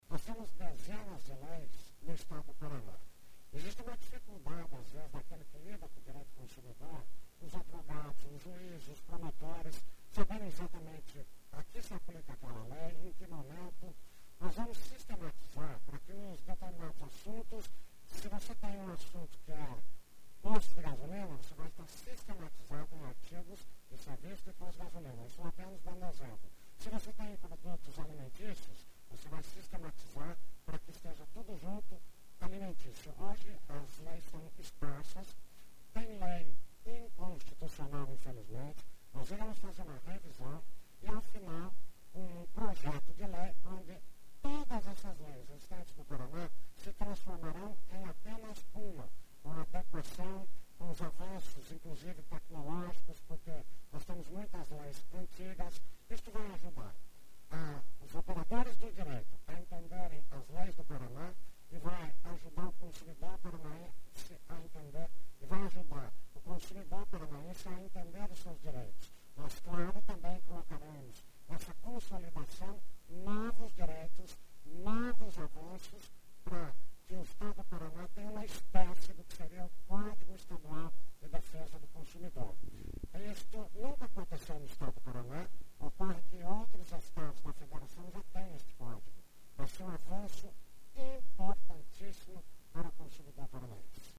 SONORA - Deputado Paulo Gomes -PP